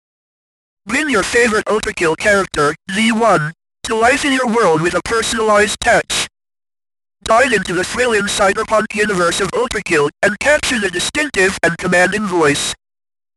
Dive into the thrilling cyberpunk universe of ULTRAKILL and capture the distinctive and commanding voice of V1 in a custom voice message tailored exclusively for you.
• Personal Greetings: Make your voicemail or phone answering message unforgettable with V1’s unique robotic tone.
V1’s voice from ULTRAKILL is a masterful blend of mechanical precision and intense energy, making it an unforgettable sound.
• High-Quality Audio: Professionally recorded and edited to ensure a crisp and clear sound.